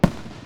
fire3.wav